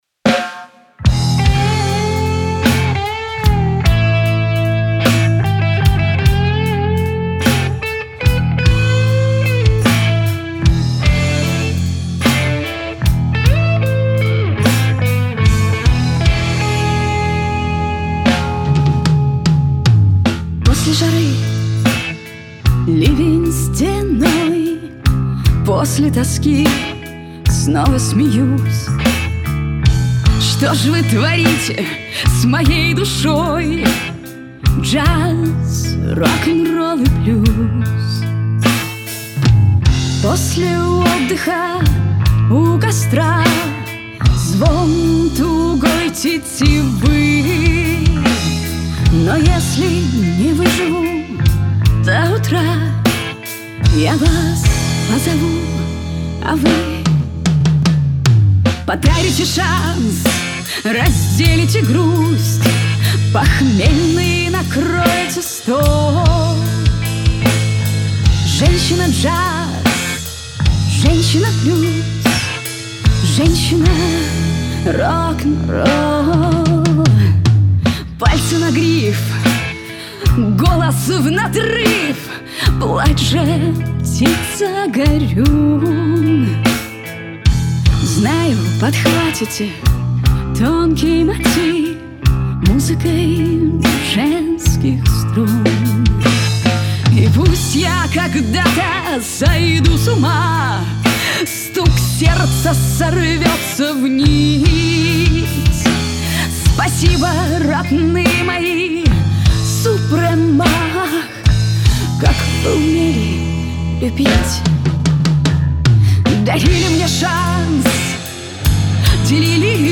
Обе песни в исполнении группы "Церера".